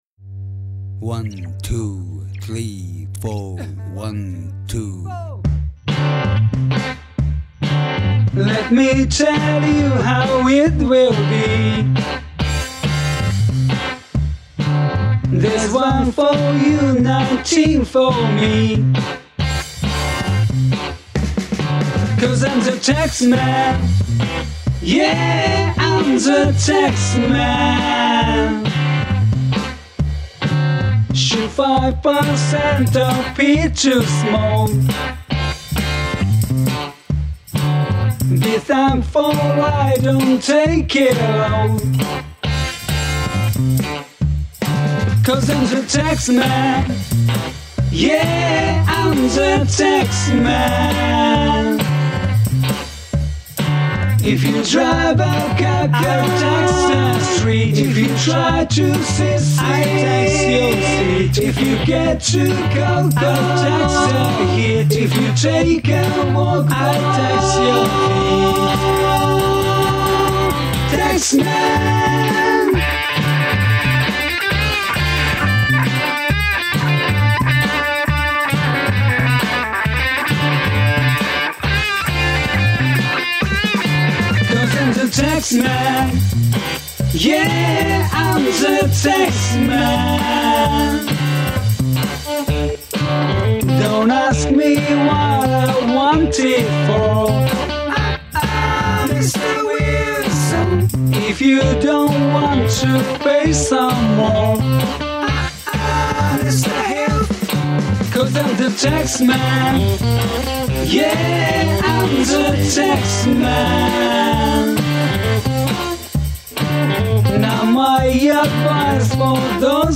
ブーンというノイズは、エレキ持ってる人ならご存知。
アンプから来たシールドジャック部をギターに挿さず、手で握るとこのノイズが出ます。
テープリバース音は、ネットからフリー素材を探しまくって切り貼り。
難関ですが今どきDAWにはレイヤー機能があり、リピートで数テイク録った中のいいとこ取り編集でごまかしてます。